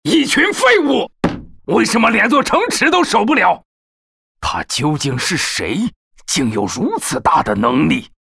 C男182号 | 声腾文化传媒
【角色】凶狠.mp3